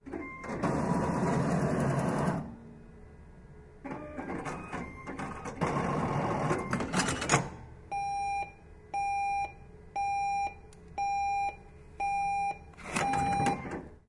自动取款机 " 自动取款机发放货币
描述：斯坦福联邦信贷联盟自动取款机发钞的录音。 使用Roland R09HR录制。